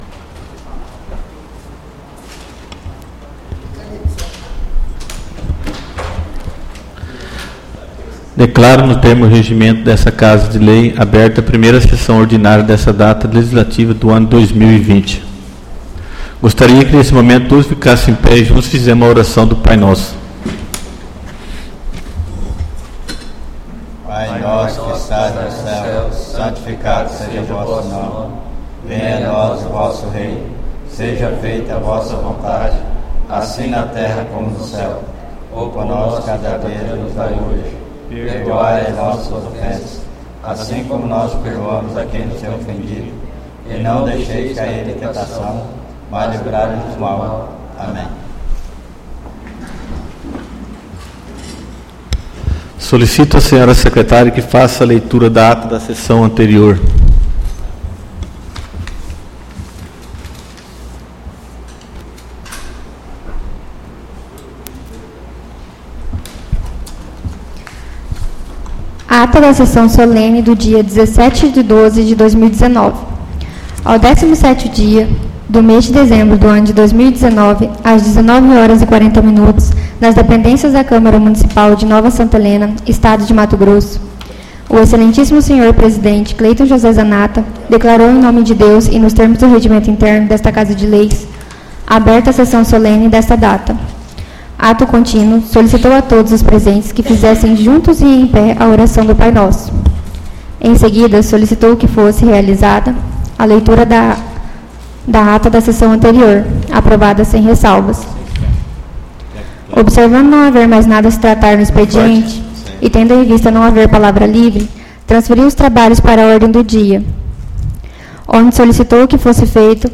ÁUDIO PRIMEIRA SESSÃO ORDINÁRIA 04/02/2020